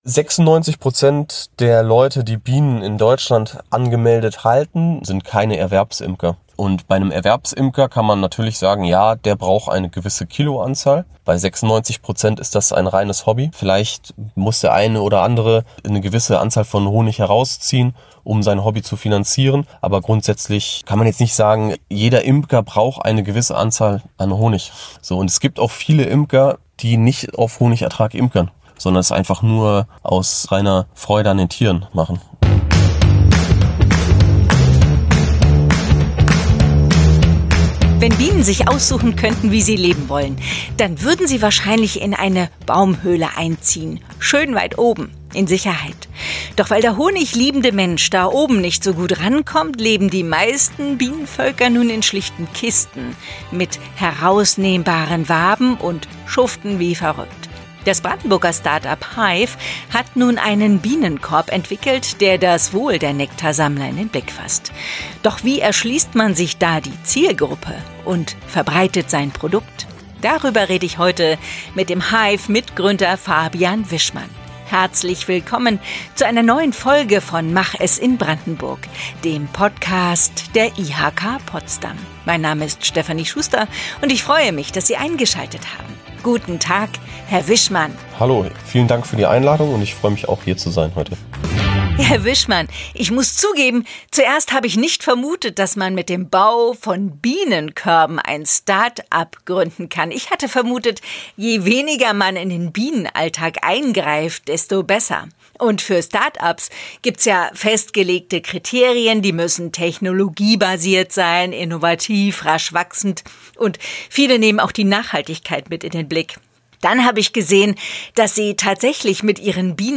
interviewt